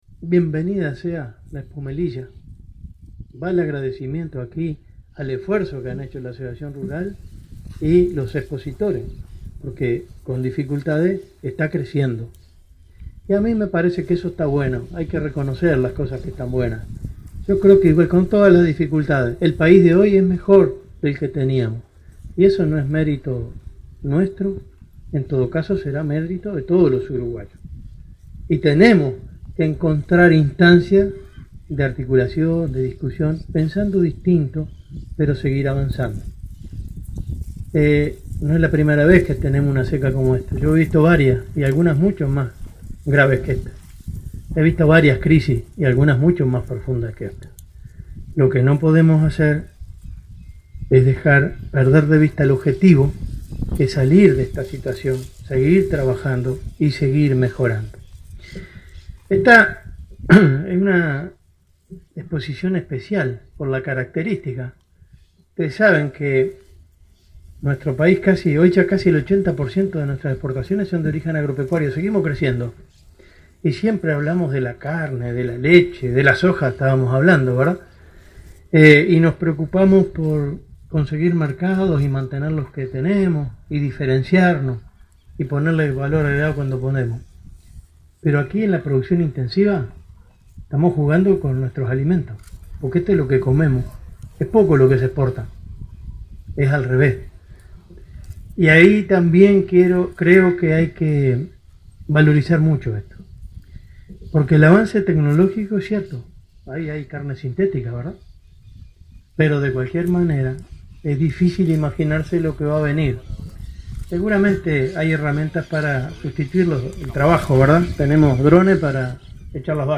Benech: Para hacer frente a las crisis agropecuarias es necesario trabajar en conjunto con conciencia agropecuaria 11/04/2018 Compartir Facebook X Copiar enlace WhatsApp LinkedIn El ministro de Ganadería, Enzo Benech, instó a lograr instancias de articulación para seguir avanzado ante crisis climáticas. En la inauguración de la 7ª edición de Expo Melilla, destacó que el tema de fondo es discutir los temas en conjunto con conciencia agropecuaria.